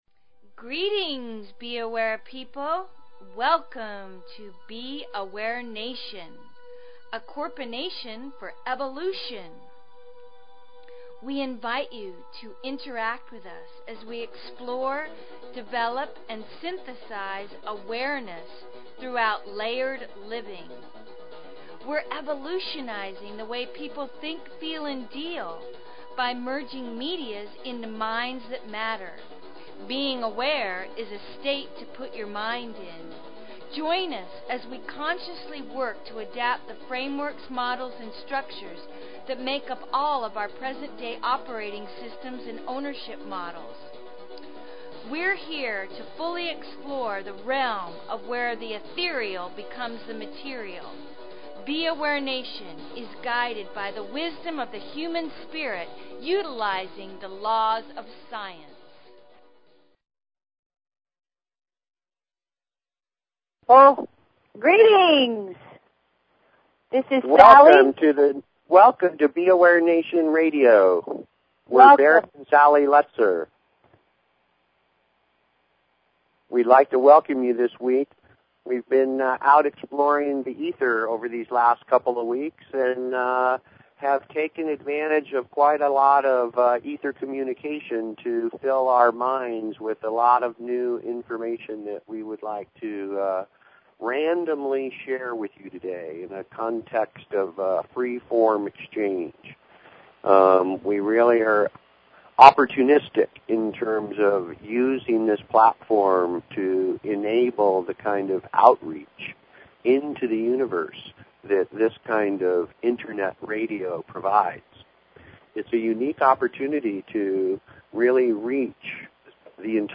Talk Show Episode, Audio Podcast, B_Aware_Nation and Courtesy of BBS Radio on , show guests , about , categorized as